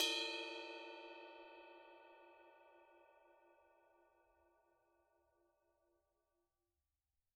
Percussion
susCymb1-hit-bell_pp.wav